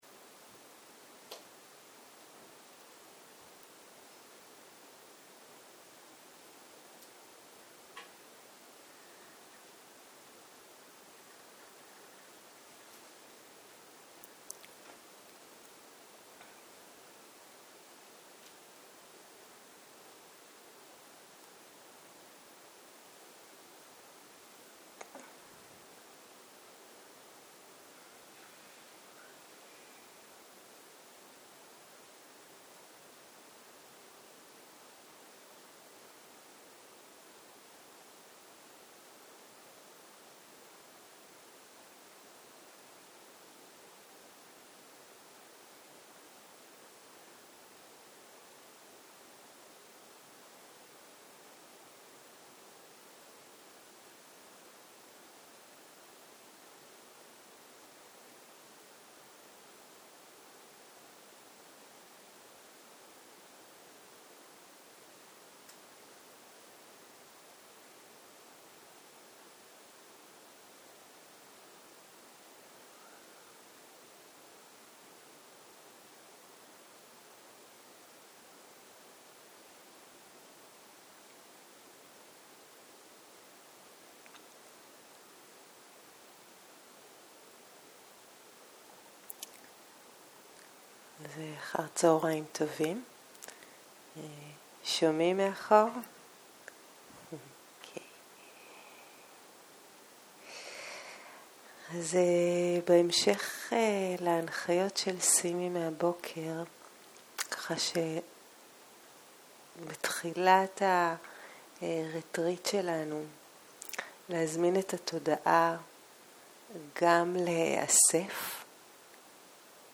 צהרים - מדיטציה מונחית
Dharma type: Guided meditation